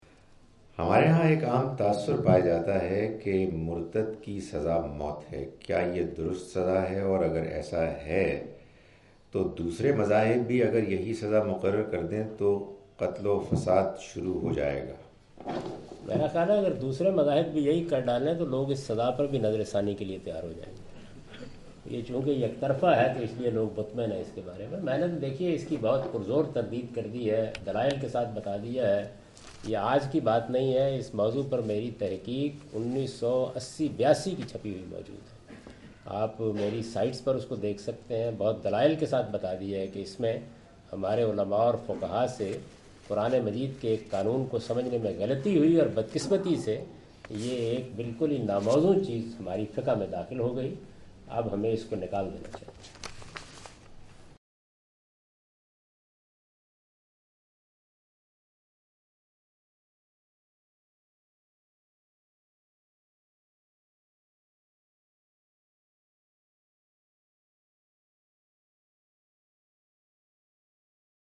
Javed Ahmad Ghamidi answer the question about "punishment of apostasy" during his visit to Manchester UK in March 06, 2016.
جاوید احمد صاحب غامدی اپنے دورہ برطانیہ 2016 کے دوران مانچسٹر میں "ارتداد کی سزا" سے متعلق ایک سوال کا جواب دے رہے ہیں۔